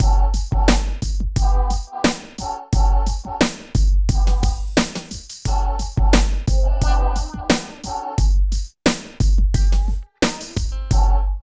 64 LOOP   -L.wav